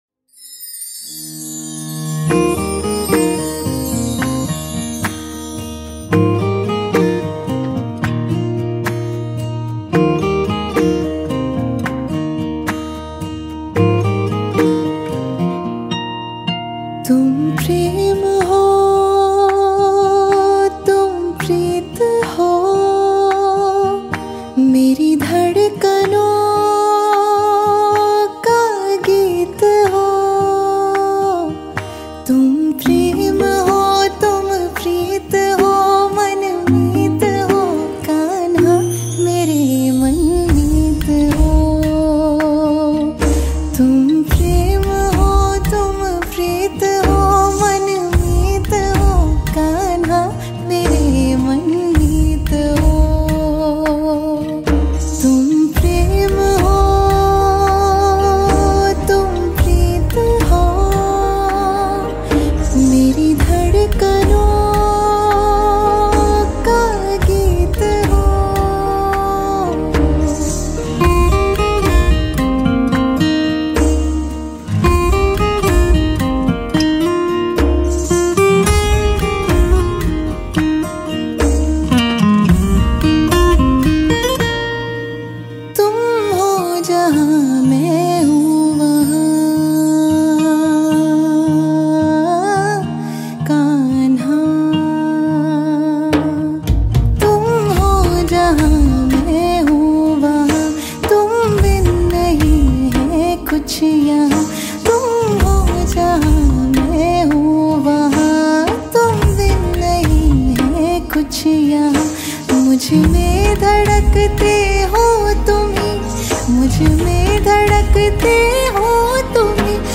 Releted Files Of Bhakti Gana